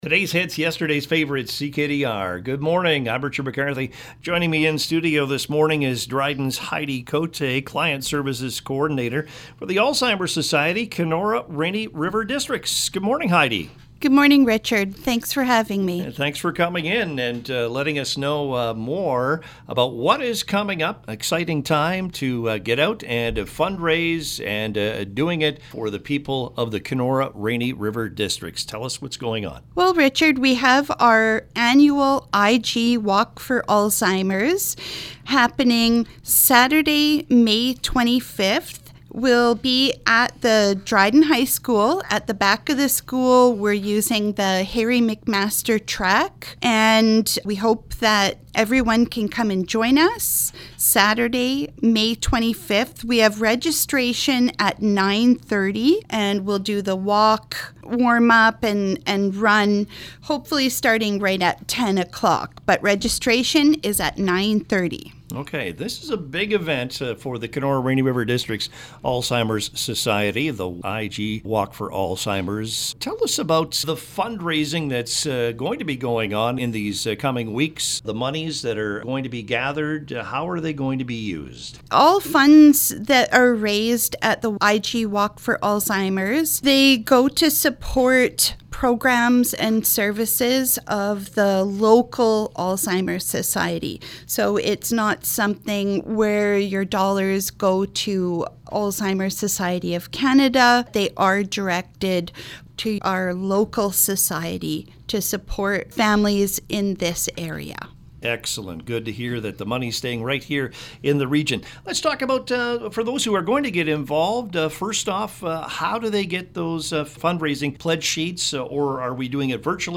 Here’s the interview: